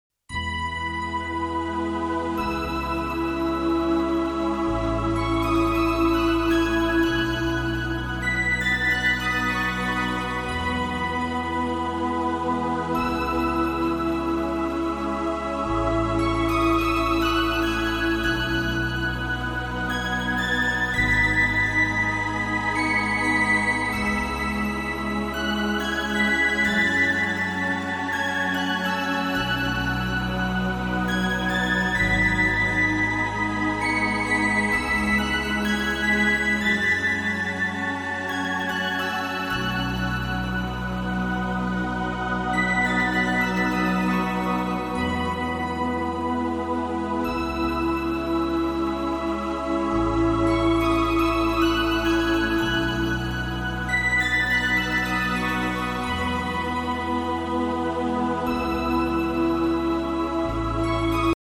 Musique new age: